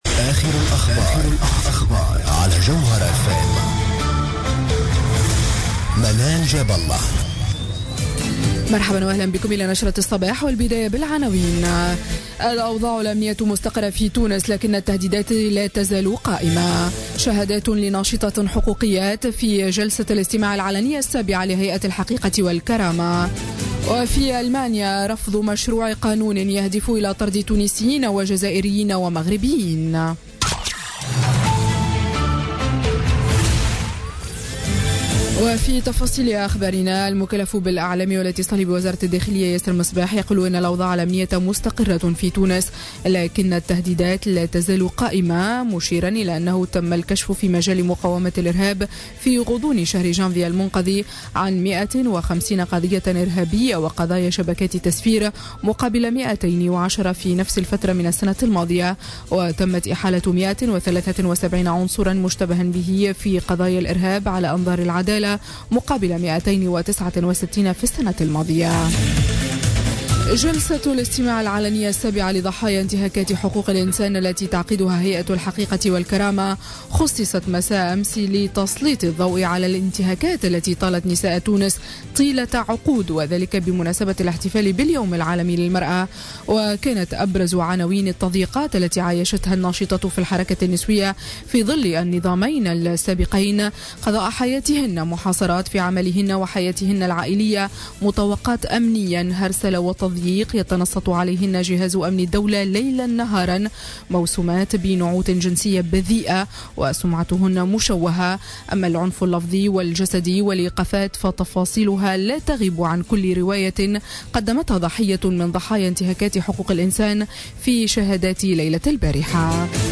نشرة أخبار السابعة صباحا ليوم السبت 11 مارس 2017